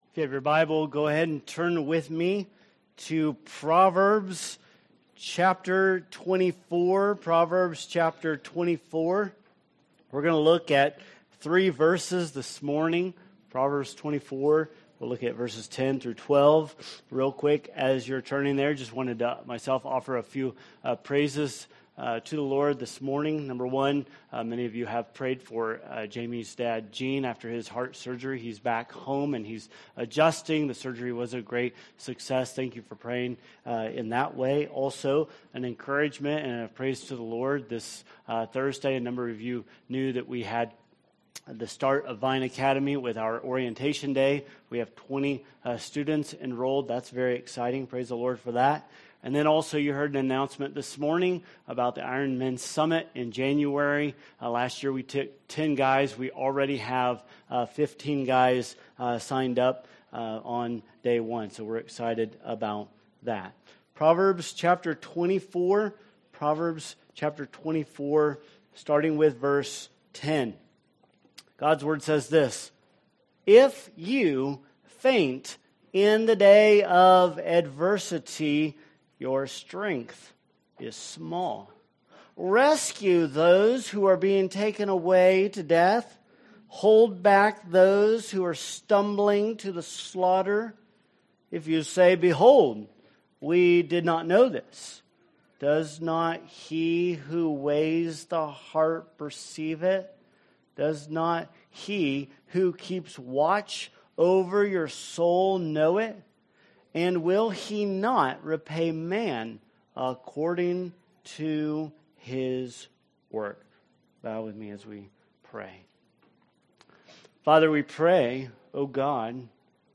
Sermons | Vine Community Church